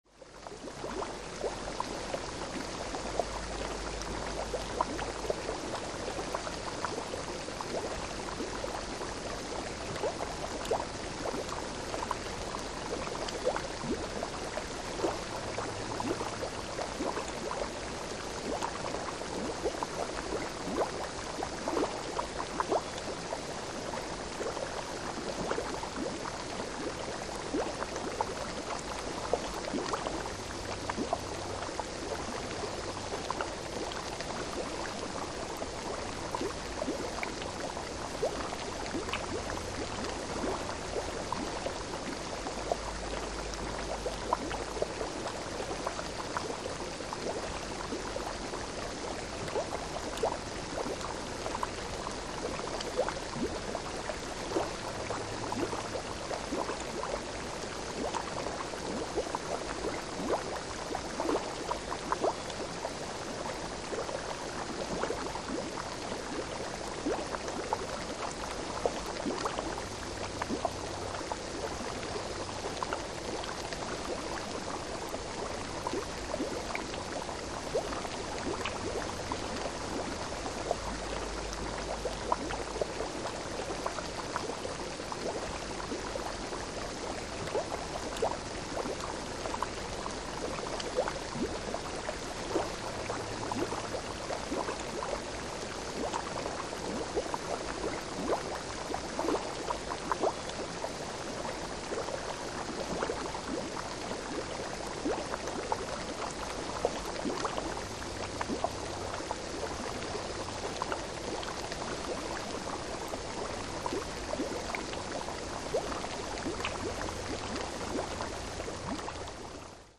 Geyser Bubbles And Hiss With Birds And Cricket Bed In Distant Background.